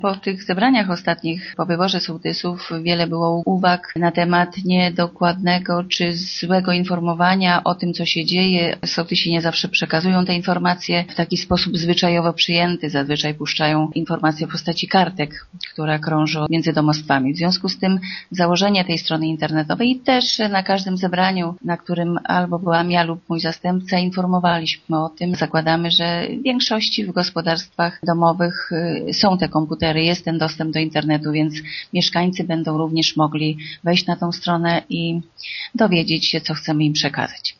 „W ten sposób chcemy zrealizować postulaty, zgłaszane na niedawnych zebraniach wiejskich, podczas których wybierano sołtysów i rady sołeckie” – mówi burmistrz Bogusława Jaworska: